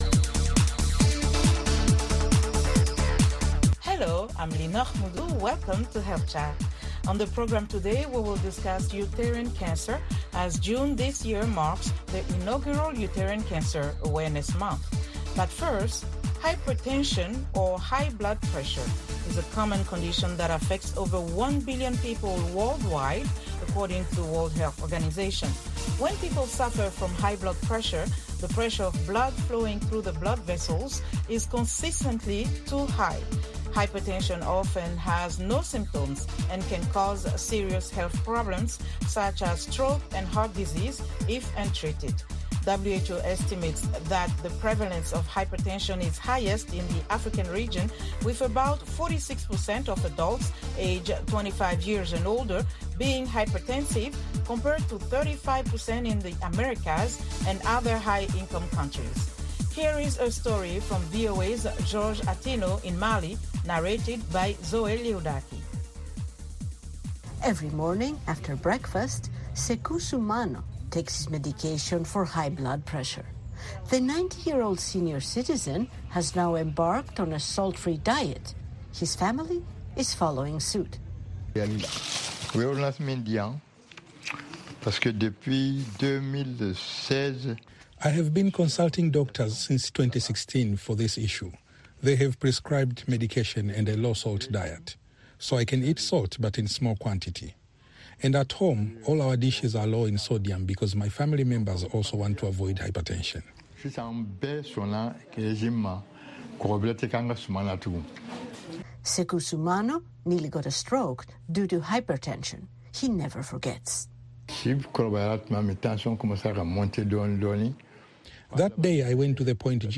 Health Chat is a live call-in program that addresses health issues of interest to Africa. The show puts listeners directly in touch with guest medical professionals. It includes a weekly feature spot, news and comments from listeners.